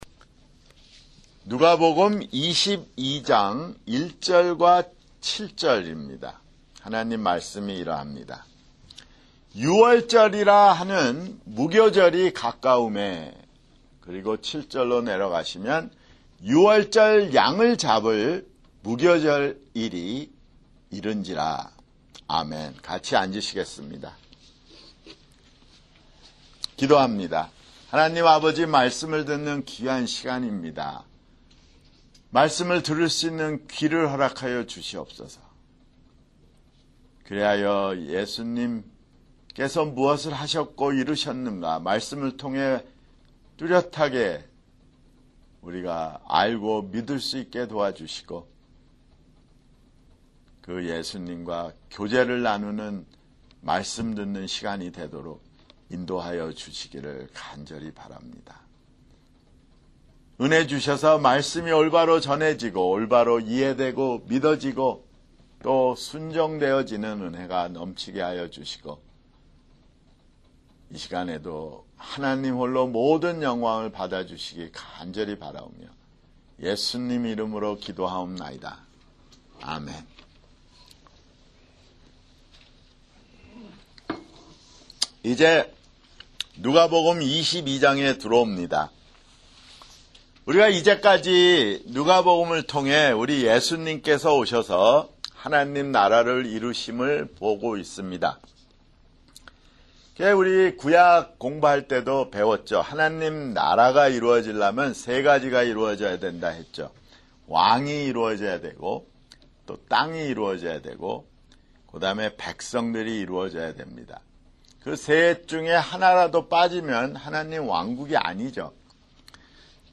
[주일설교] 누가복음 (146)